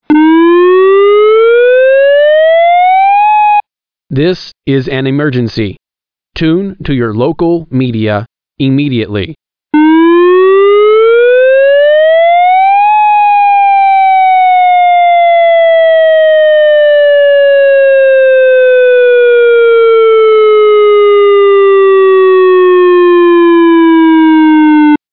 Emergency Warning
The emergency tone will sound like this:
Emergency-Warning-MP3